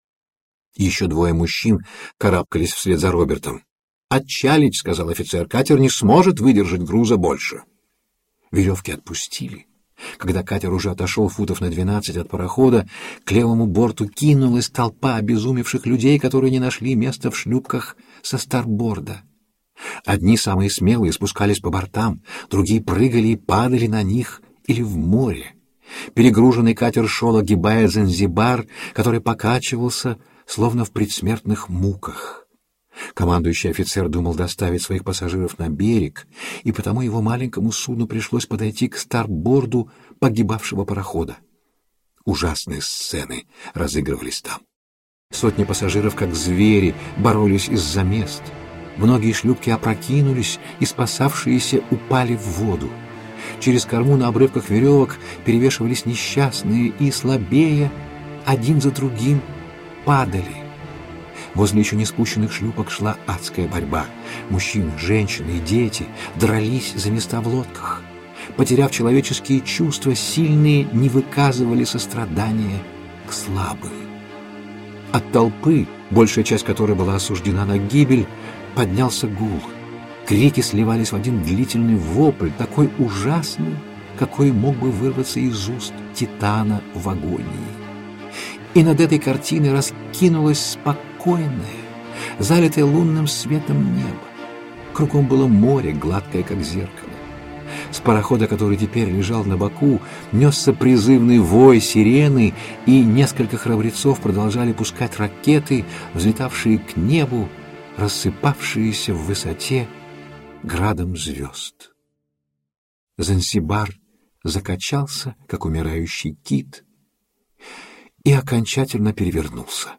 Аудиокнига Бенита | Библиотека аудиокниг